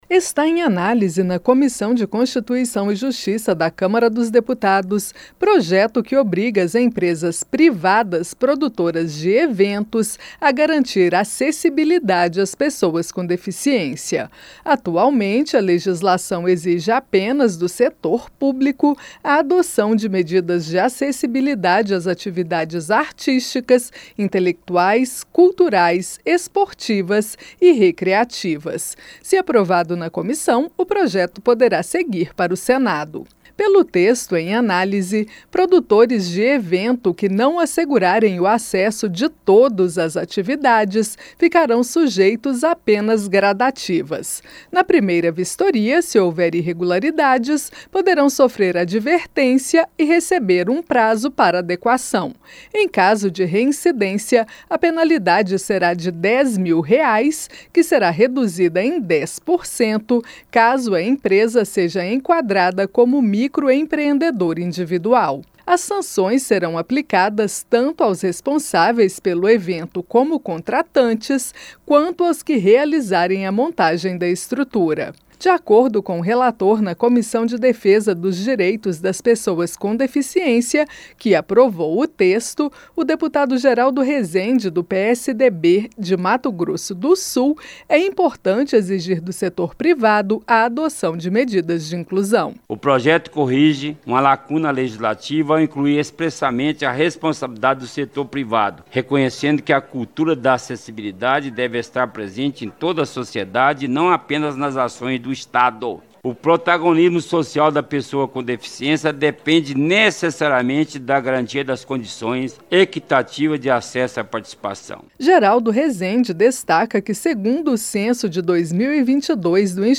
COMISSÃO ANALISA PROJETO QUE OBRIGA PRODUTORES PRIVADOS DE EVENTOS A ADOTAR MEDIDAS DE ACESSIBILIDADE. A REPÓRTER